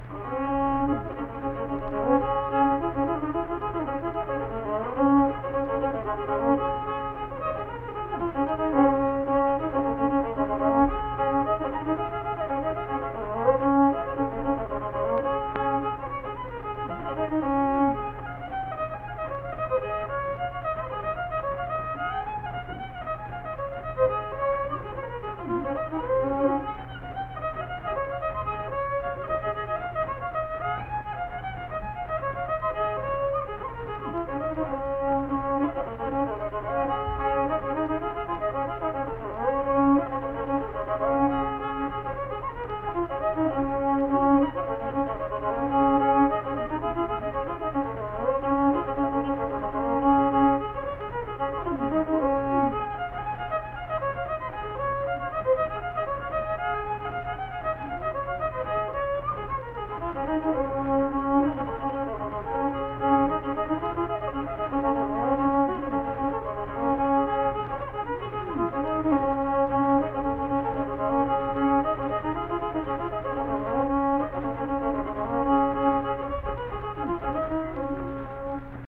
Unaccompanied fiddle music performance
Instrumental Music
Fiddle
Mannington (W. Va.) , Marion County (W. Va.)